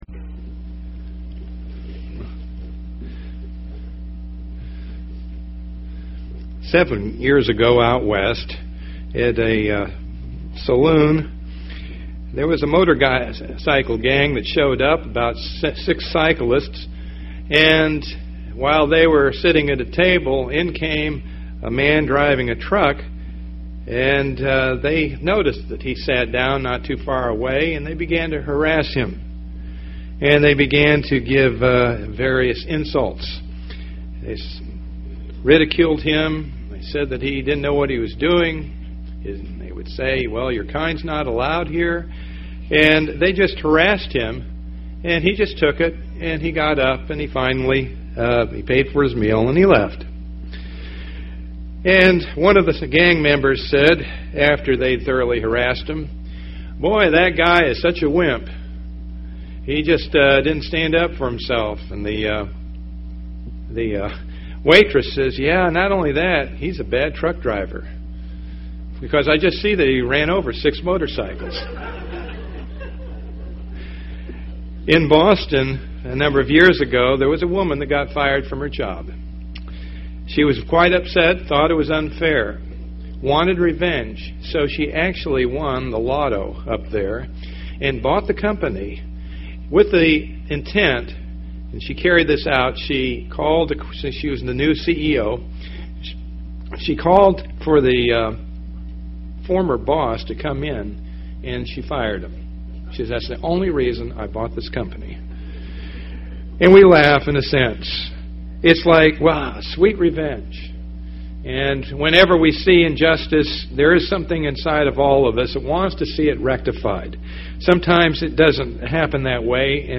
Given in Tampa, FL St. Petersburg, FL
UCG Sermon Studying the bible?